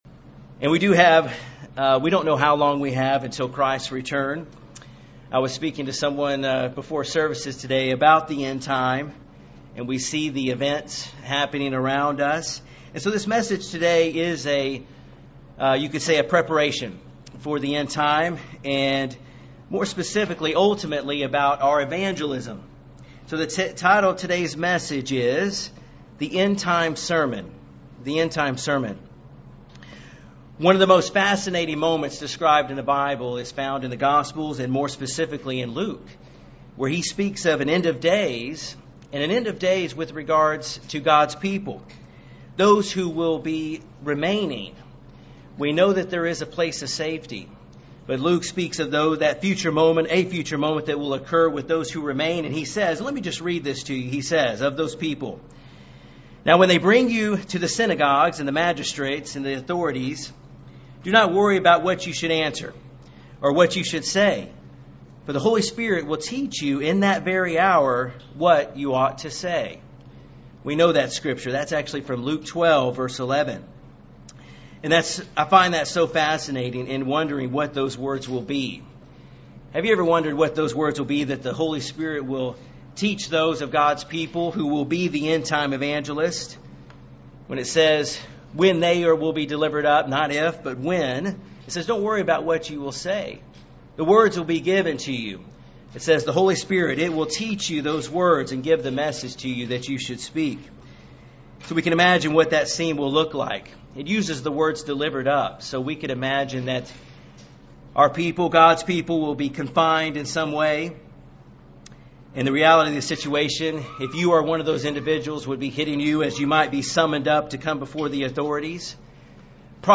The End Time Sermon